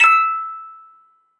musicbox " do kruis 1
描述：一个单音的音乐盒玩具，做十字架/C，可用于键盘映射
标签： 金属 音乐盒 请注意 样品 玩具
声道立体声